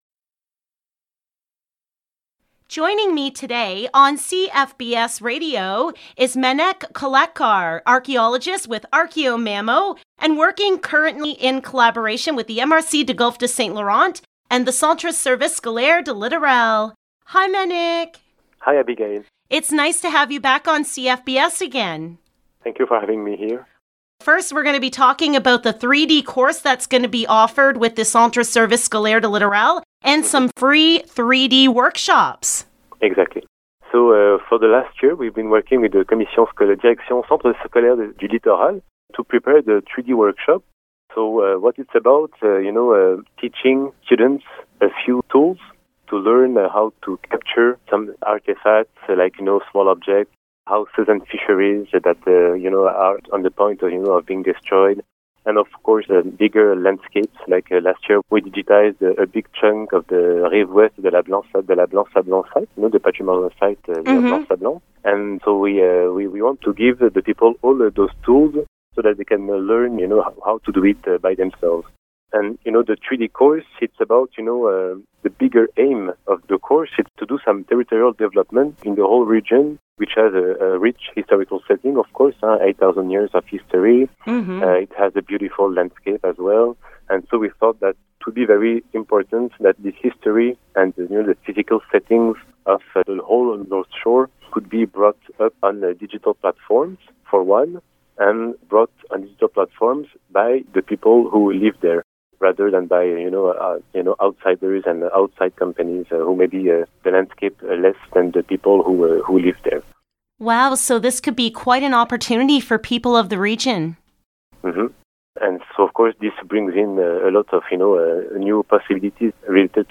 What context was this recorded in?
LOCAL NEWS - AUGUST 11, 2021 - Learn 3D imaging training with archaeologists on the Lower North Shore!